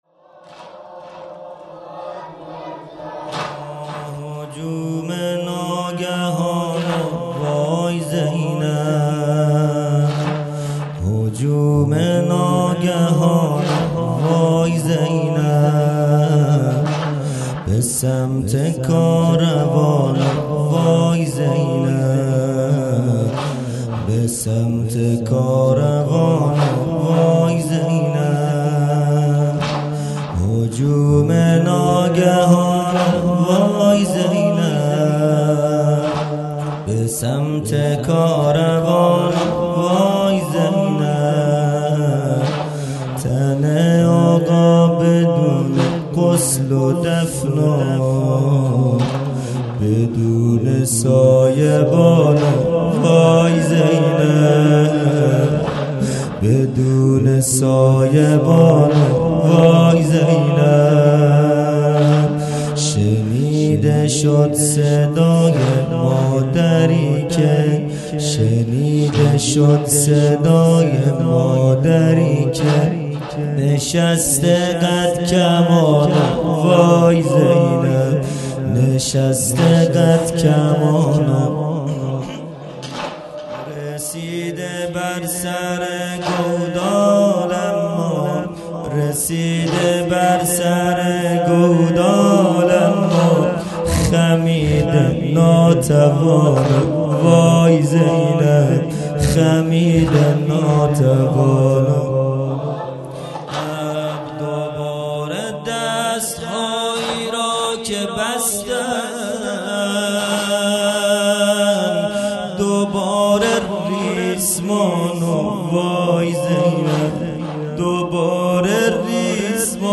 0 0 واحد دوم | هجوم ناگهان و وای زینب
دهه اول محرم الحرام ۱۴۴٢ | شام غریبان